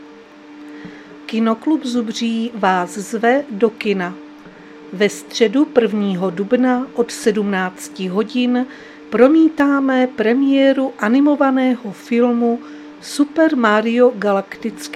Záznam hlášení místního rozhlasu 30.3.2026
Zařazení: Rozhlas